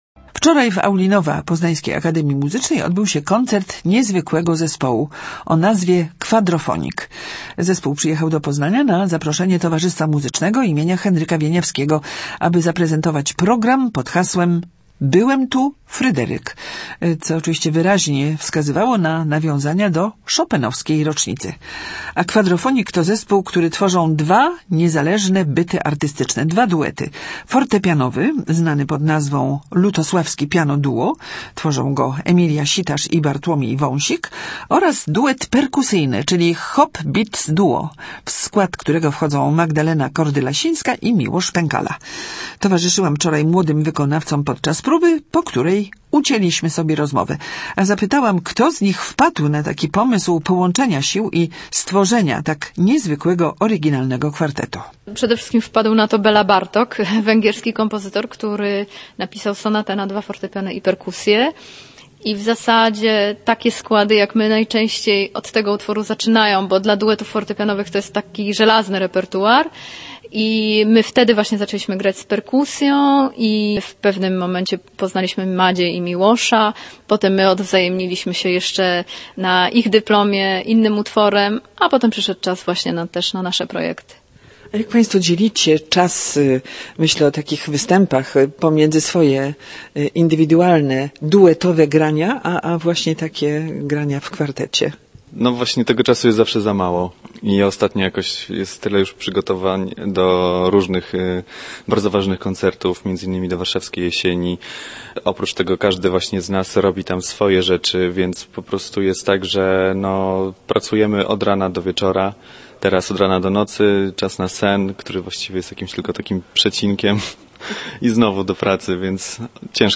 W Auli Nova poznańskiej Akademii Muzycznej koncertował w ostatnią niedzielę zespół "Kwadrofonik". Powstał z połączenia dwóch duetów: fortepianowego i perkusyjnego.